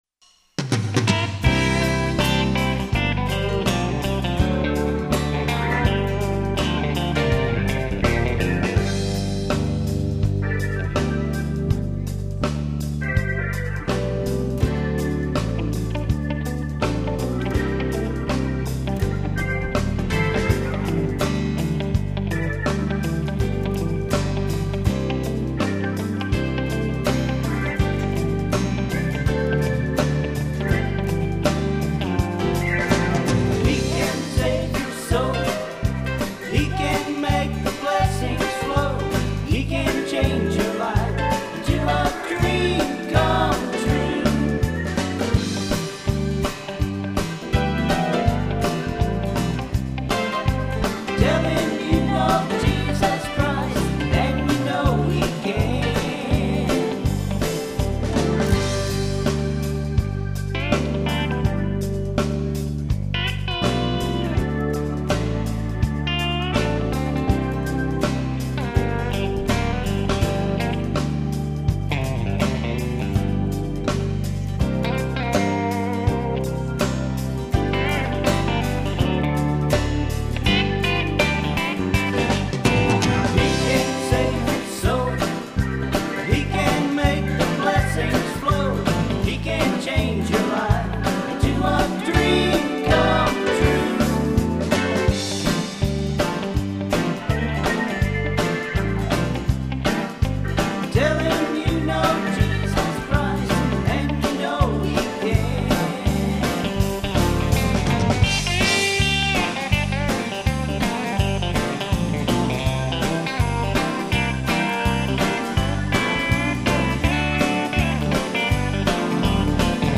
Christian Gospel Music I Know He Can I Know He Can Well I never opened blind eyes, I can't save a soul.